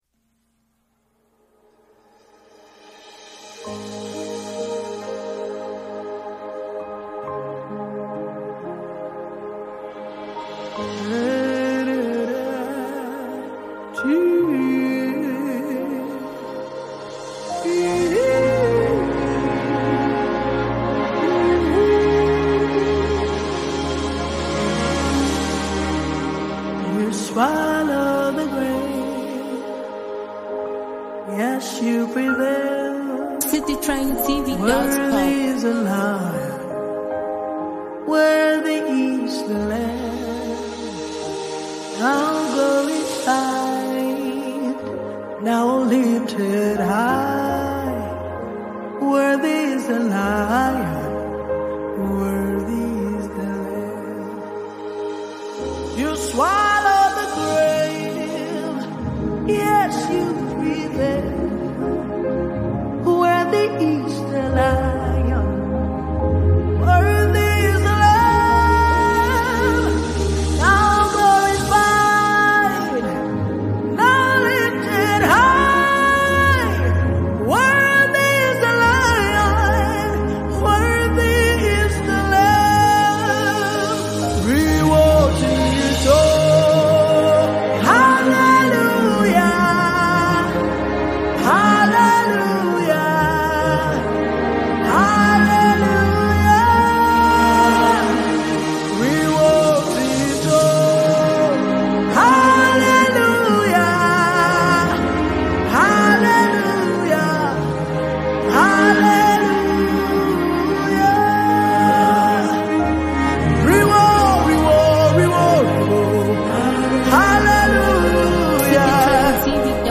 powerful and spirit-filled worship song